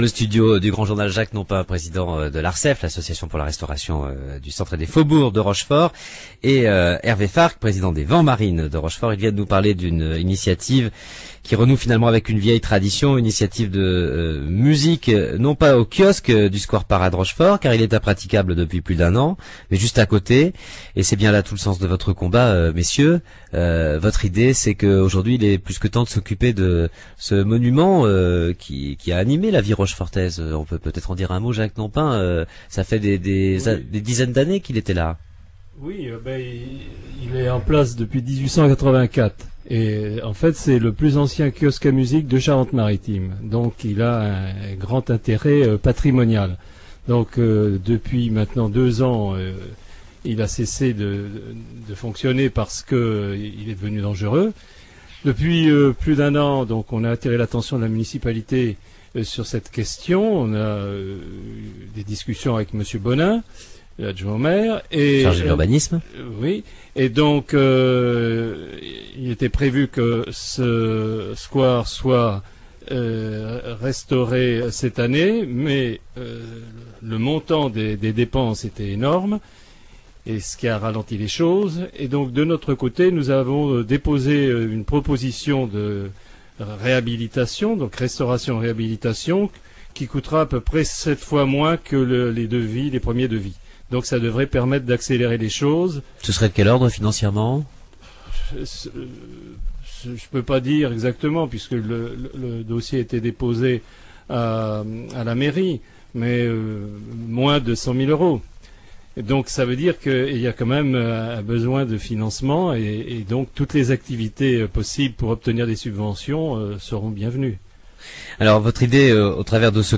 Interventions dans les journaux de
journal-Part2.ra